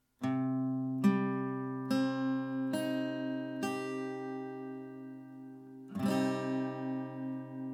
Der c-Moll-Akkord besteht aus den drei Tönen: C, Es und G, die auch als Dreiklang bezeichnet werden.
c-Moll (Barré, A-Saite)
C-Moll-Akkord, Gitarre
C-Moll-Barre-A.mp3